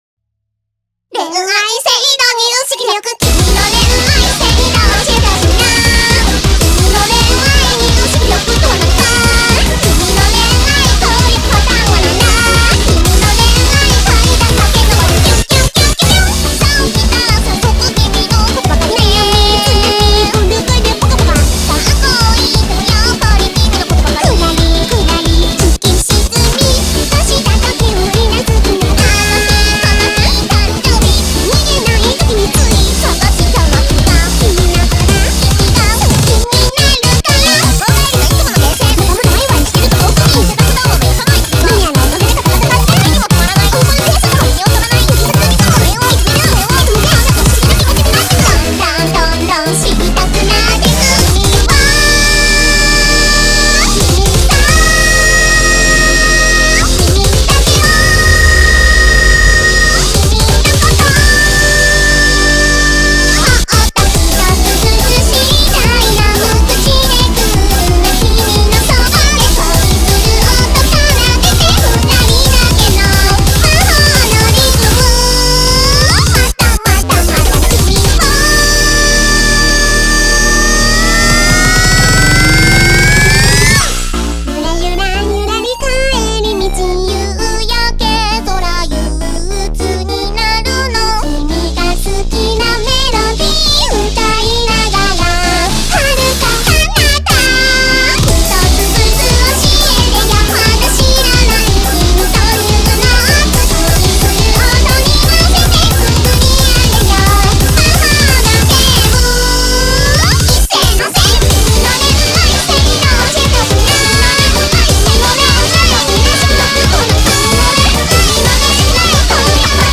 BPM3-177